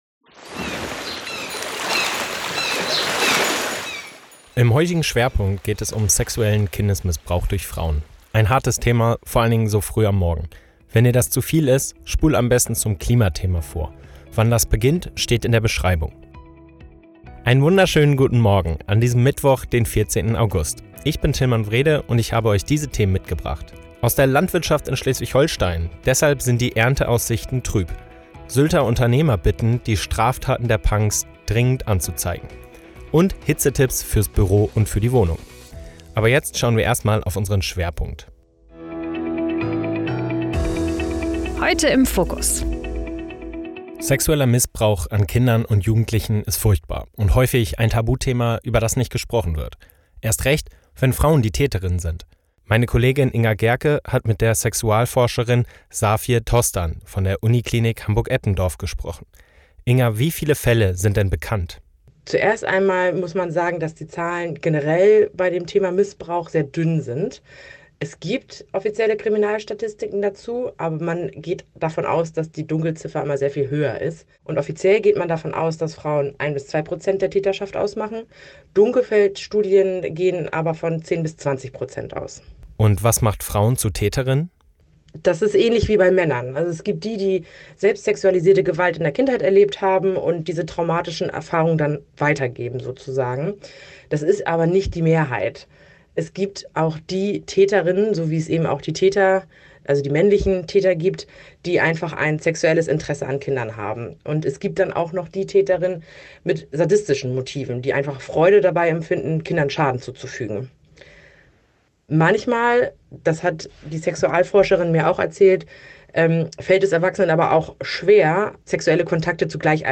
erläutert eine Sexualforscherin im Interview.